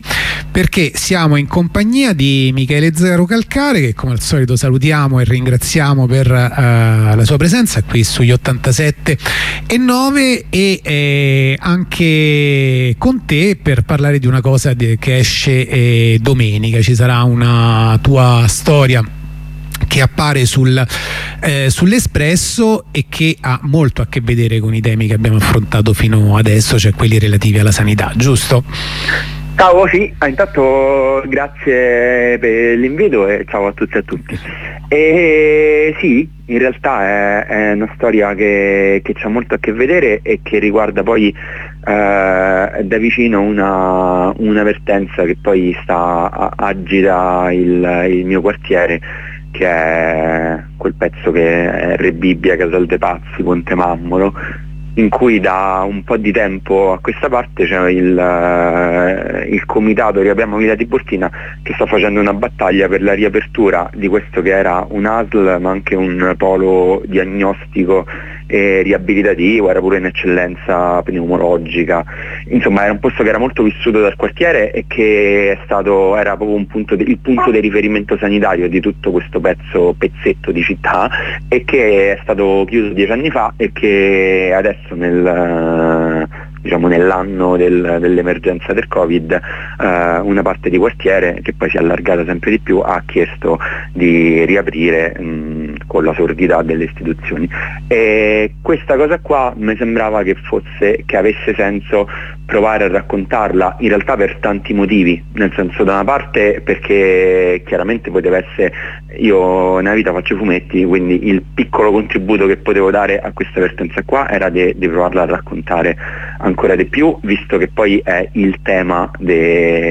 Esce domenica l'ultimo lavoro di Zerocalcare sulla riapertura del polo socio-sanitario Villa Tiburtina e sul generale stato della sanità pubblica. Ne abbiamo parlato con lui questa mattina